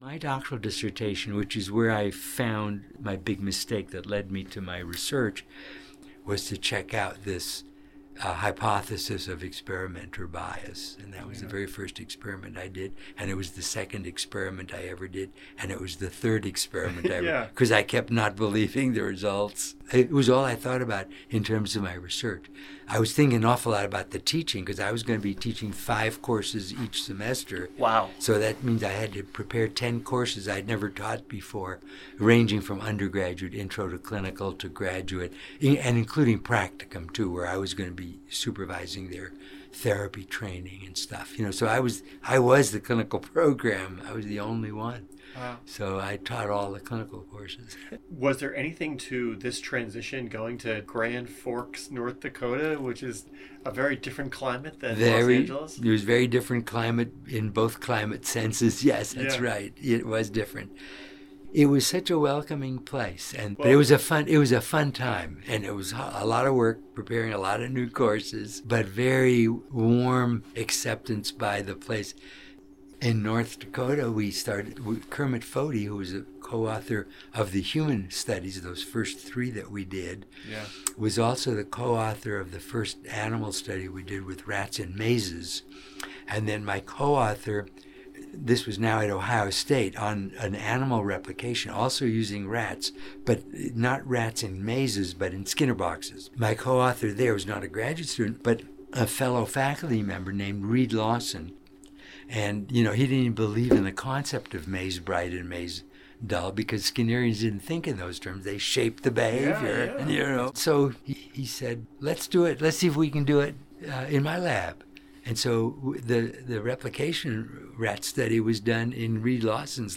I am pleased to present some tidbits from our conversation — all recalled by Dr. Rosenthal with his characteristic kindness and joviality.